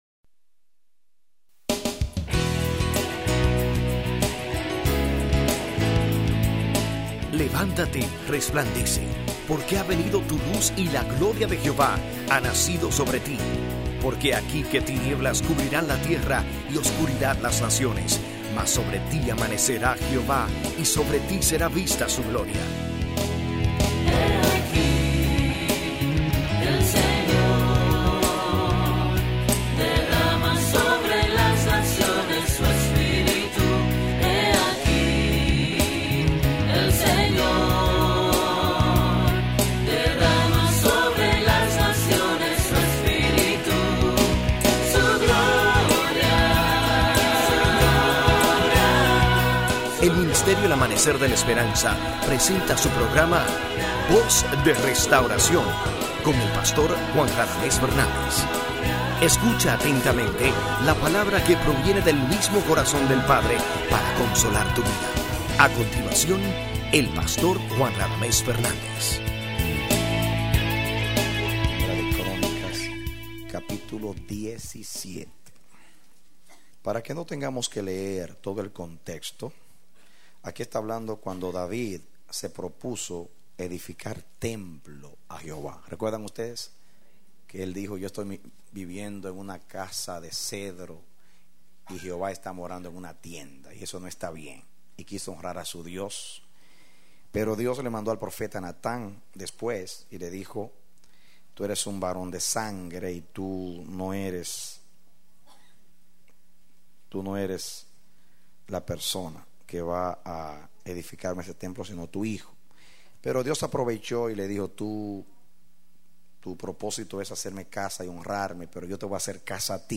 Mensaje: “Puso Su Nombre”